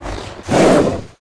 Index of /App/sound/monster/ice_snow_dog
attack_1.wav